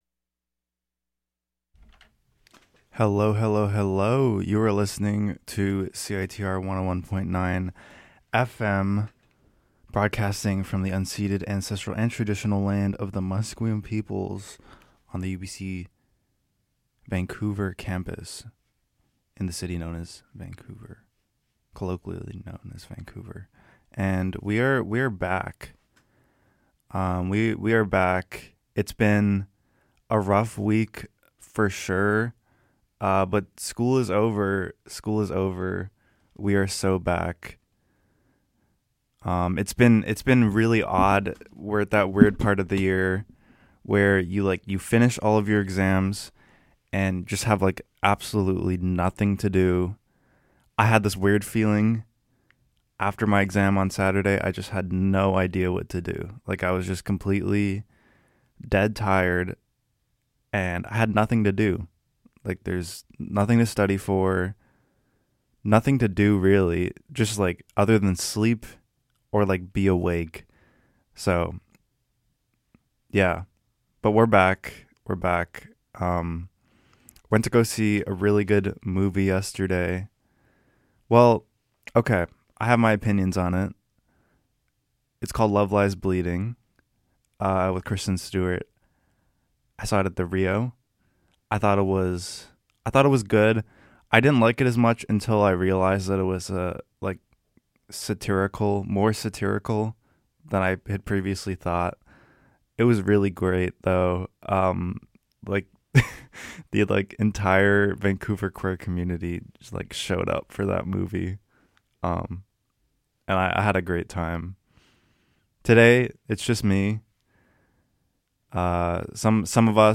WE GET GAZEY, DRAINED, THEN VERY LOUD! <3